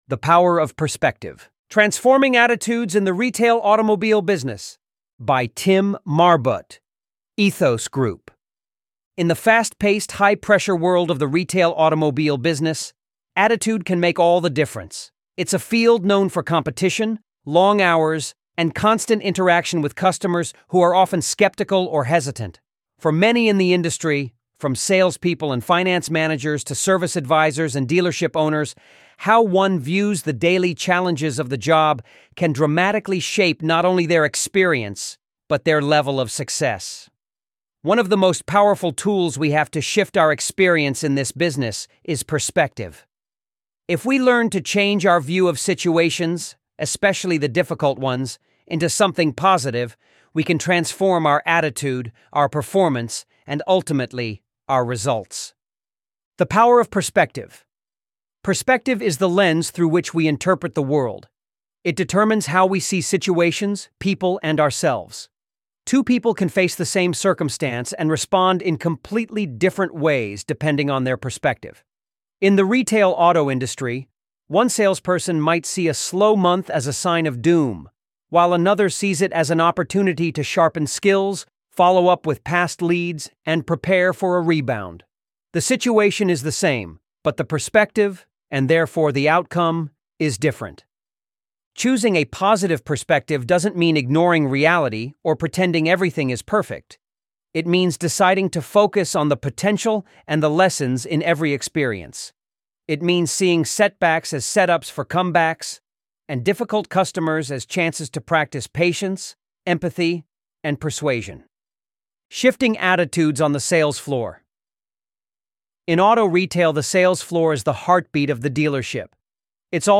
ElevenLabs_The_Power_of_Perspective.mp3